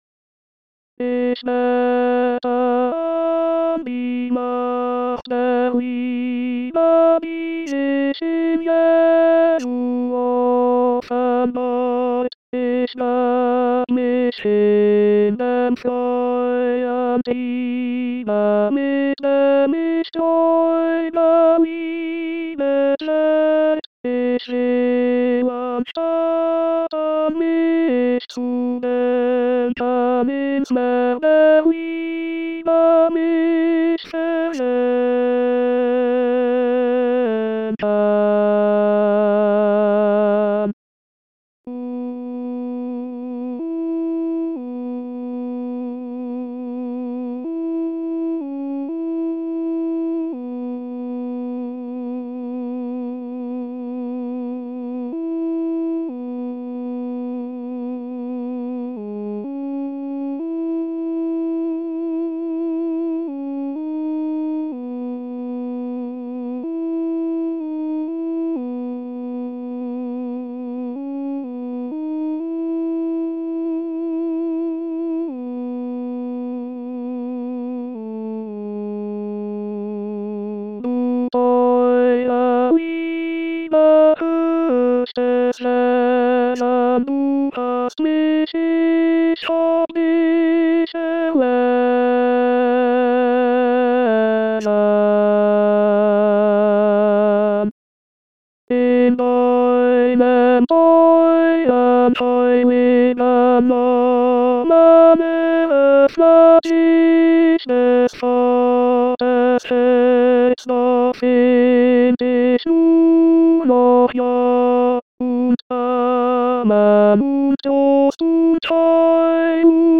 ich bete an die Macht der Liebe tenors 2.mp3